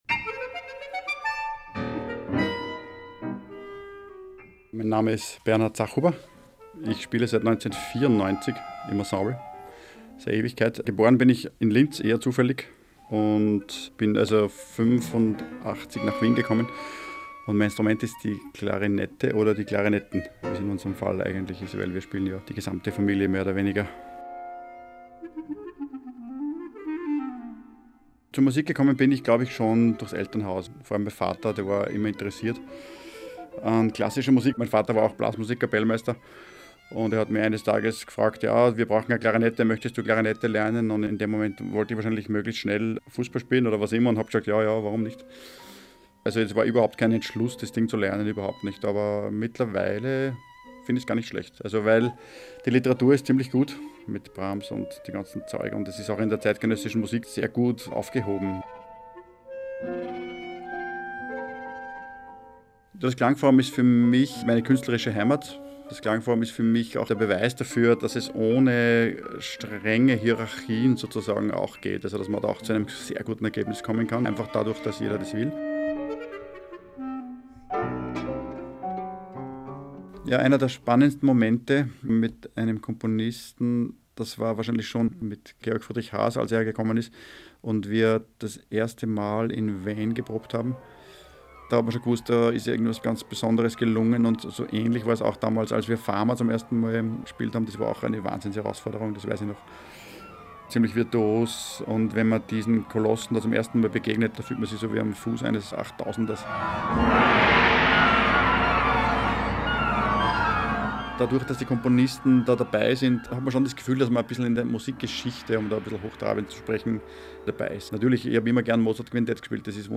Klavier
Klarinette